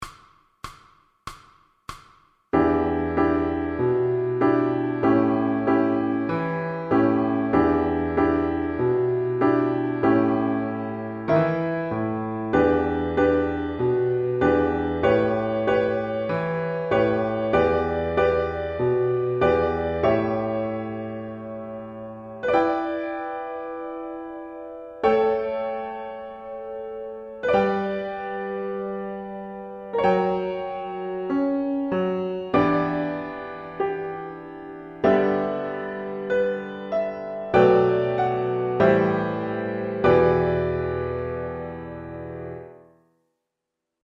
recorded piano accompaniments
Exam Speed